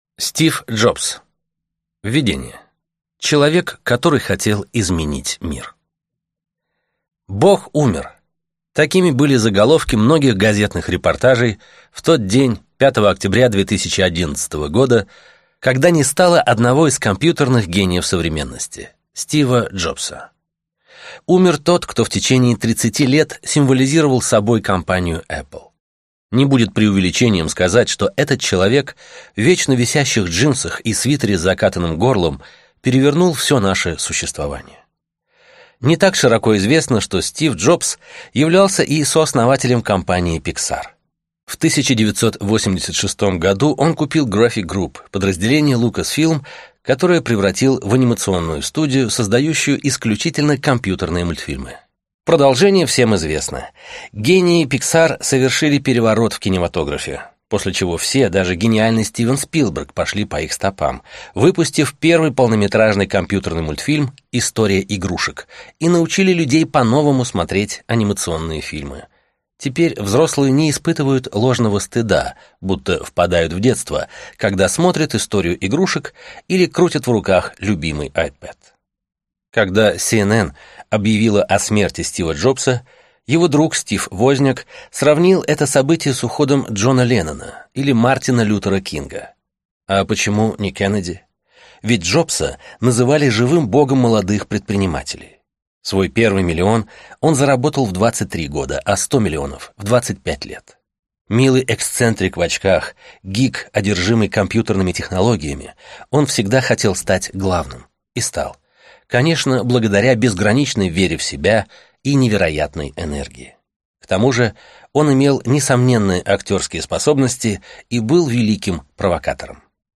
Аудиокнига Стив Джобс | Библиотека аудиокниг